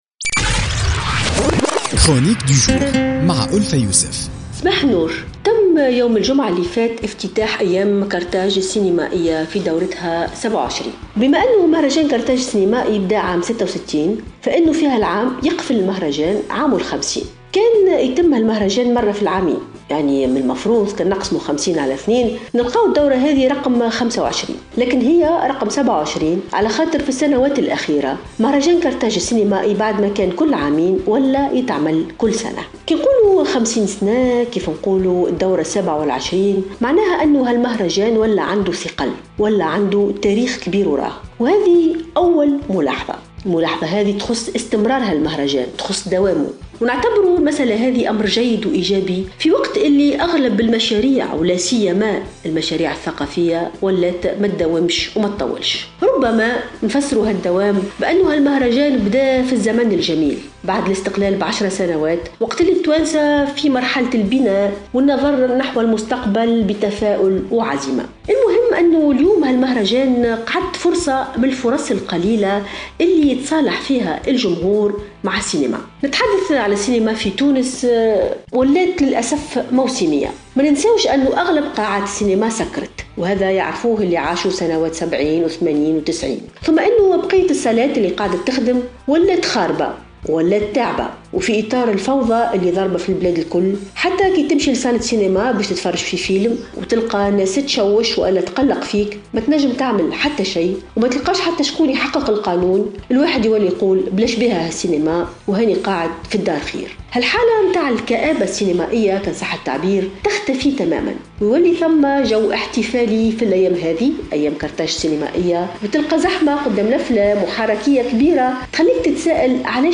تحدثت الباحثة ألفة يوسف في افتتاحية اليوم الاثنين 31 أكتوبر 2016 عن السينما في تونس.